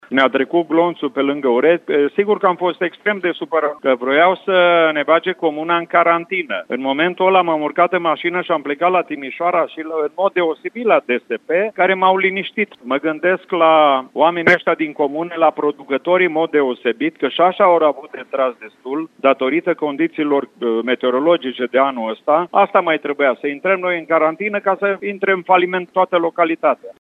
Primarul Gheorghe Nastor spune că în cazul în care comuna ar fi intrat în carantină, cel mai mult ar fi avut de suferit cultivatorii de lubeniță.